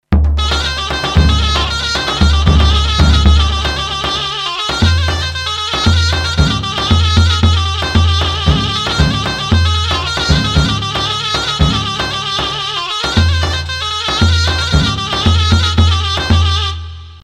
Nefesli Çalgilar Samples
Asagida Dinlediginiz Sample Sesleri direk Orgla Calinip MP3 Olarak Kayit edilmistir
ZURNA2.MP3